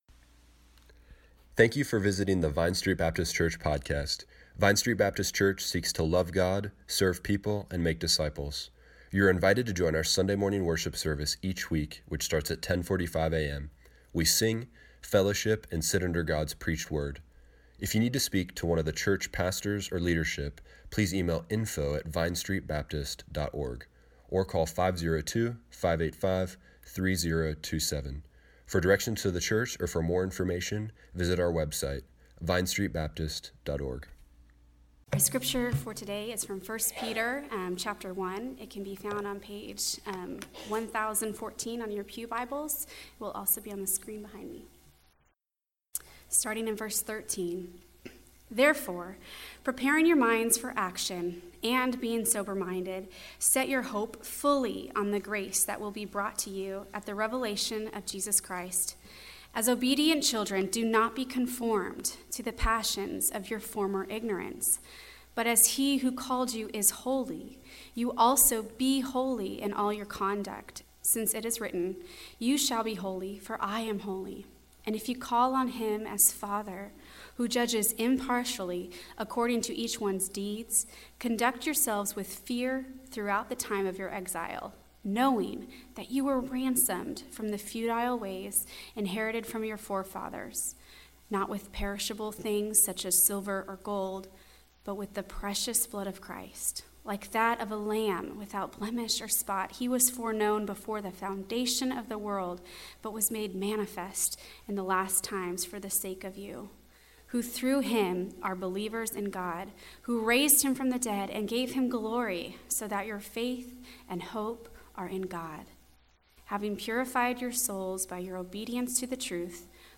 Service Morning Worship
sermon